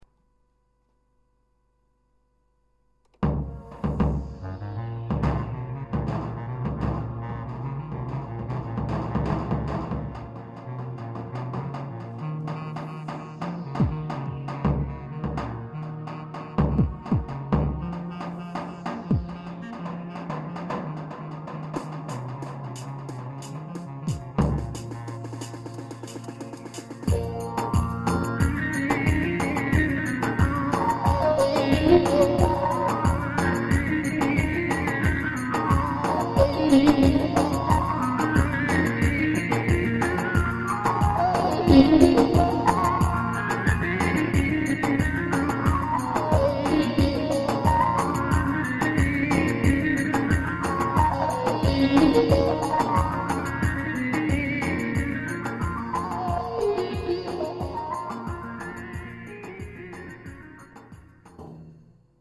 korg pa 55 tr ELEKTRO BAĞLAMA (yeni)
arkadaşlar bu elektro bağlamayı yeni yazdım,özellikle doğu halayları için sizce nasıl idealmi,nasıl olmuş beğendinizmi,elektro hakkında yorumlarınızı bekliyorum,olumlu yada olumsuz yorumlara açığım, demodaki elektro beğenenler için set aşağıdadır